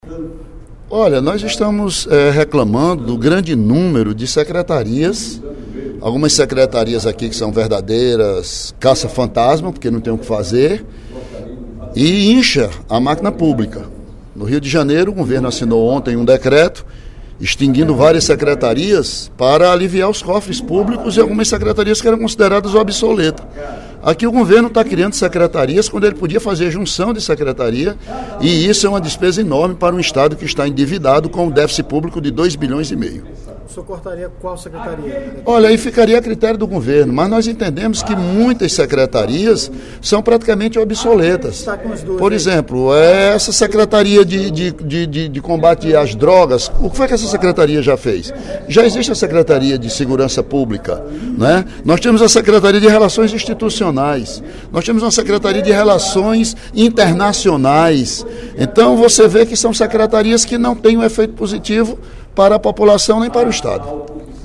O deputado Ely Aguiar (PSDC) criticou, durante o primeiro expediente da sessão plenária desta sexta-feira (10/06), os gastos que o Estado tem com secretarias que, segundo ele, são “obsoletas”.